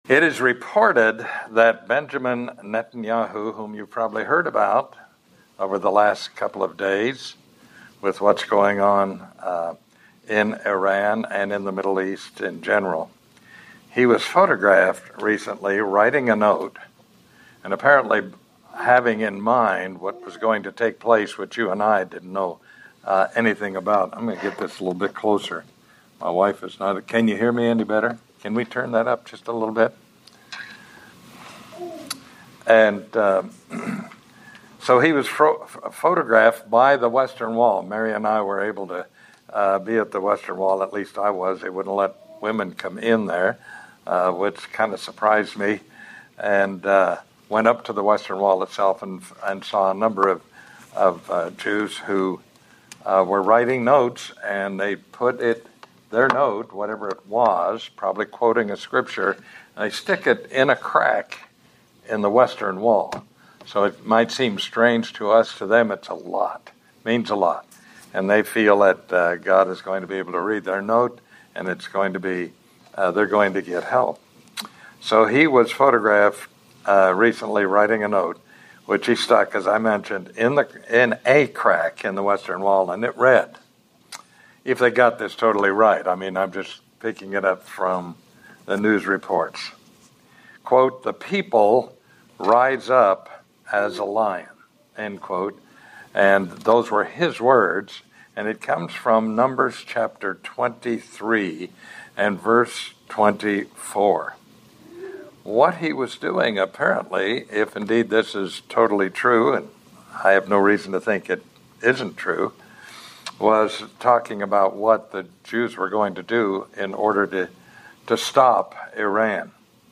Will this new incursion lead to a wider war? Could it lead directly into the end-time Biblical events leading to the Christ's return? These questions are addressed in this sermon.